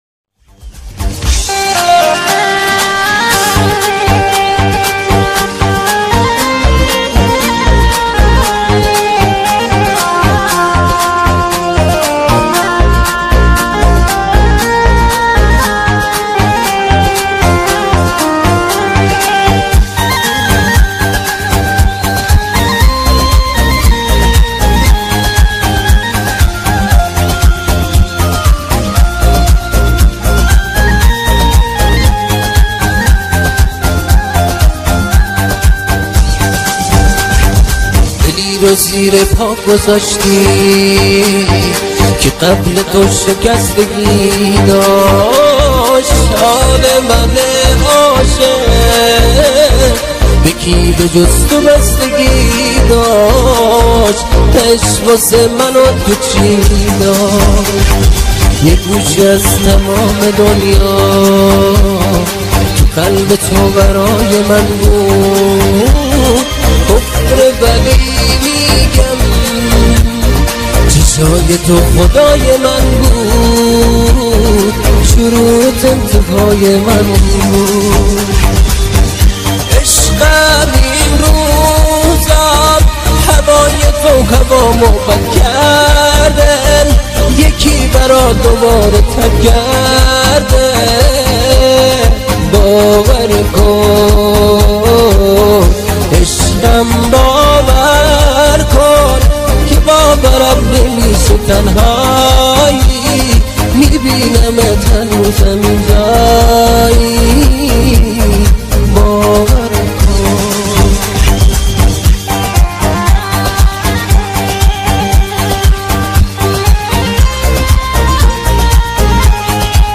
تند بیس دار MP3